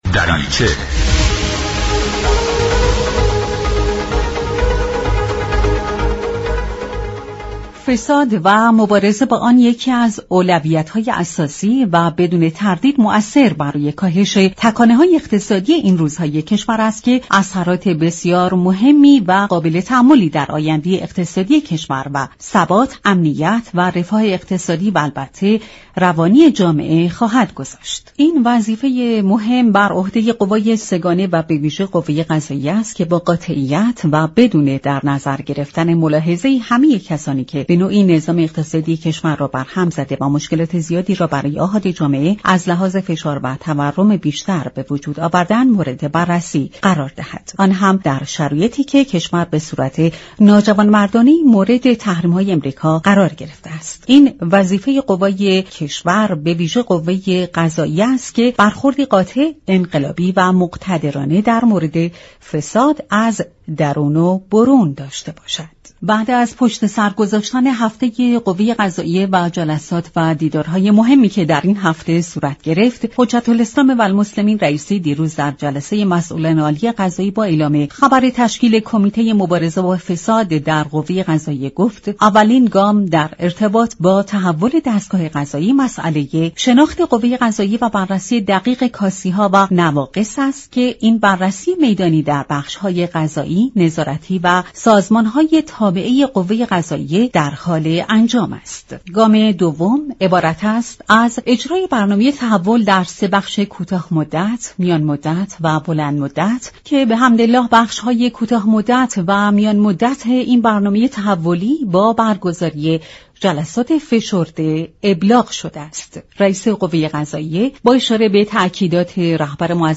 به گزارش شبكه رادیویی ایران؛ محمد علی پور مختار عضو كمیسیون حقوقی- قضایی مجلس در گفت و گو با برنامه «جهان سیاست» به بحث فساد در كشور و راهكارهای جدید قوه قضاییه در خصوص آن اشاره كرد و گفت: اعلام آراء صادره از محاكم، شفافیت بیشتر آراء را منجر می شود و كار نقد و اظهار نظر را برای جامعه حقوقی آسان تر می كند.